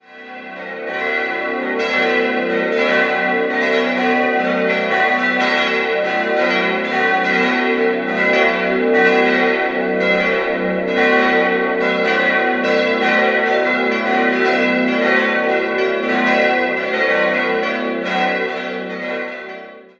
Aus der Vorgängerkirche von 1868, die wegen statischer Probleme abgebrochen werden musste, stammt die Innenausstattung. 5-stimmiges Geläute: g'-b'-c''-d''-f'' (tief) Die Glocken 1 und 3 wurden 1987 von Perner in Passau, die Glocken 2 und 4 in den Jahren 1949 und 1950 von Kuhn-Wolfart in Lauingen und die kleinste 1921 von Hamm in Augsburg gegossen.